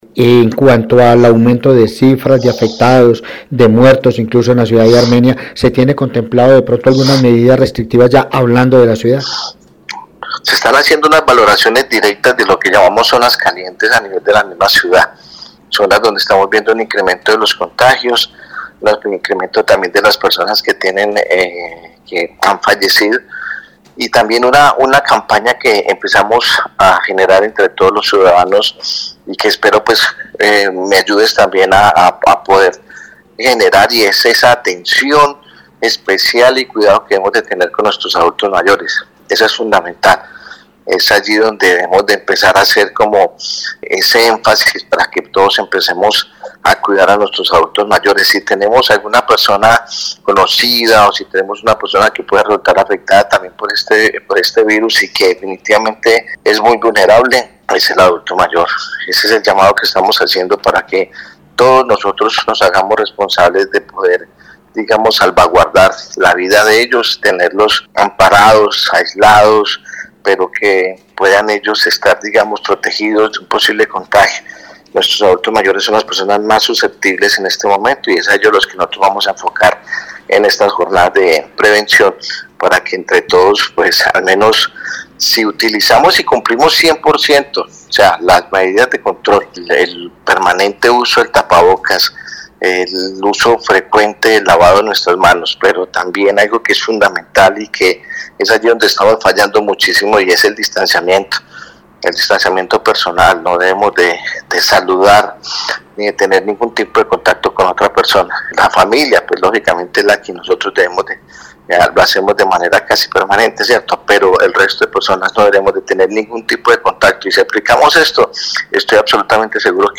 Periodismo Investigativo dialogó con el Alcalde de Armenia Dr. José Manuel Ríos Morales y sobre su salud explicó que: